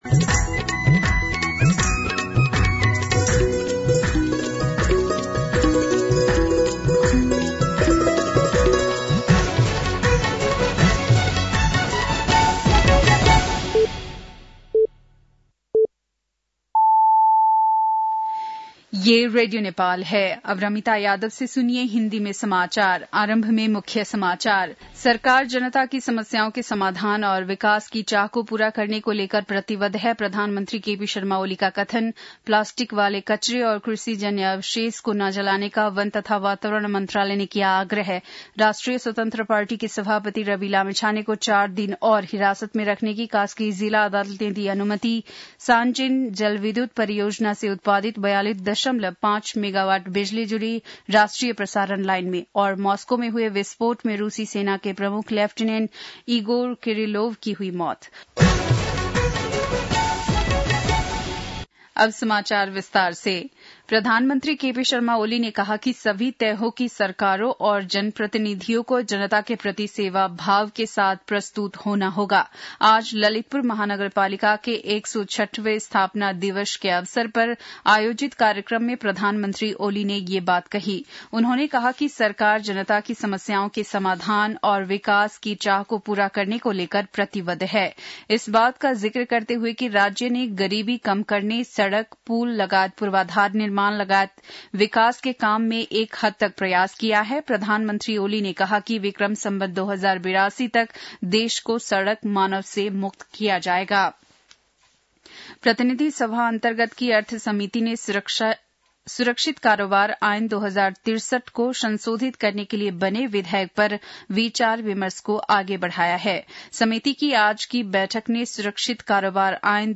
बेलुकी १० बजेको हिन्दी समाचार : ३ पुष , २०८१
10-PM-Hindi-News-9-2.mp3